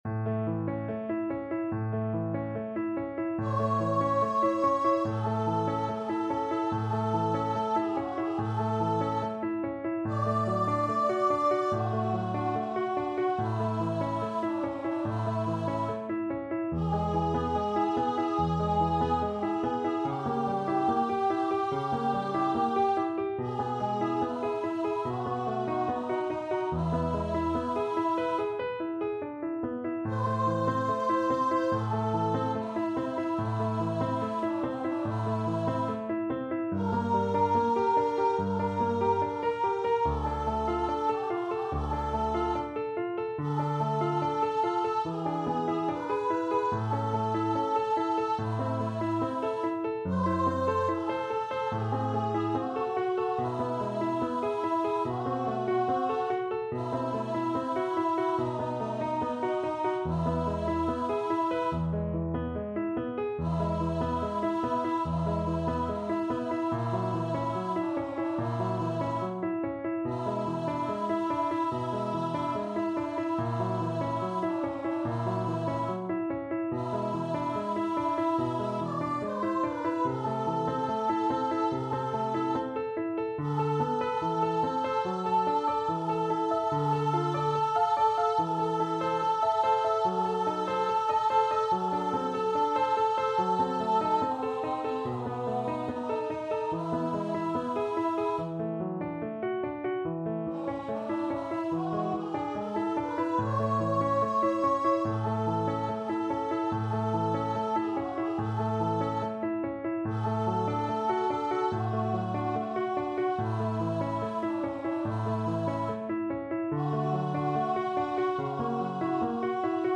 A major (Sounding Pitch) (View more A major Music for Vocal Duet )
Andante =72
Classical (View more Classical Vocal Duet Music)